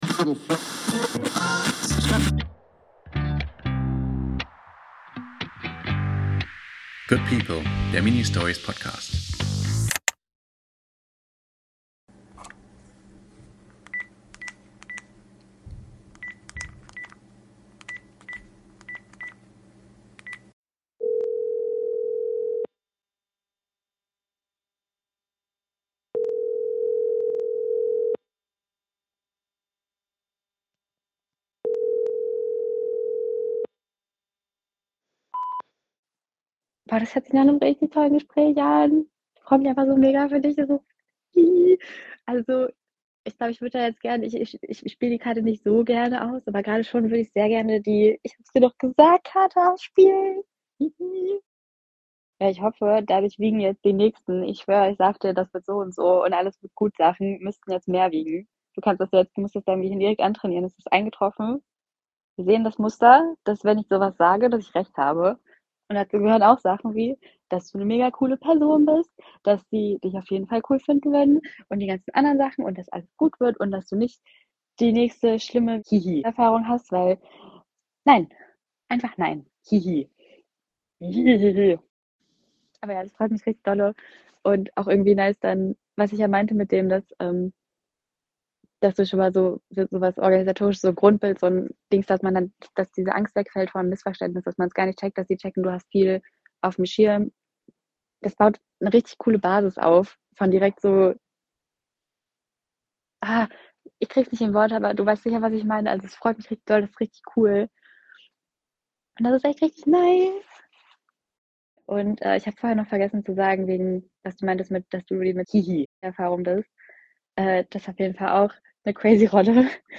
Es tutet. Einmal, zweimal, dreimal – dann der Signalton.
Eine Stimme, warm, fast ein bisschen stolz. Sie sagt, sie will ja eigentlich nicht, aber diesmal muss sie die „Ich hab’s dir doch gesagt“-Karte spielen.
Sie freut sich mit dir – echt, tief, liebevoll, mit einem Staunen darünber, dass alles so kommen durfte.